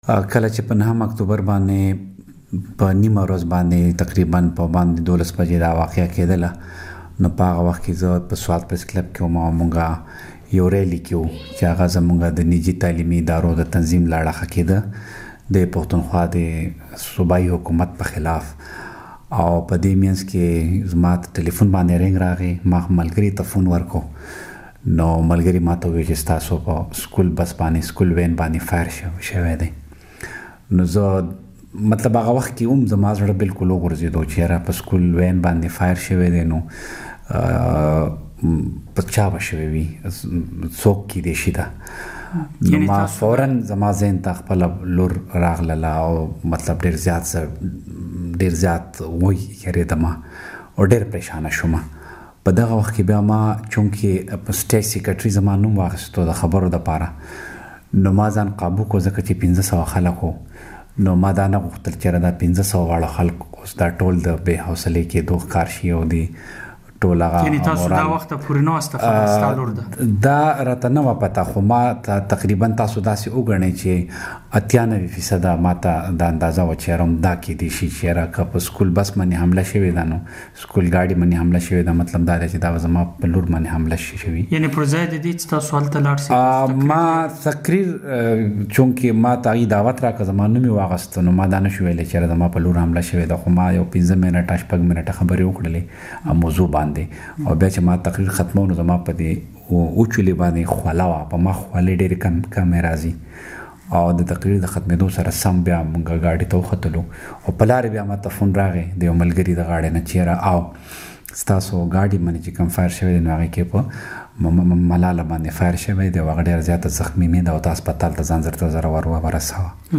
د ملالې له پلار ضیا الدین یوسفزي سره مرکه